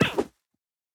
Minecraft Version Minecraft Version 1.21.4 Latest Release | Latest Snapshot 1.21.4 / assets / minecraft / sounds / mob / armadillo / roll1.ogg Compare With Compare With Latest Release | Latest Snapshot
roll1.ogg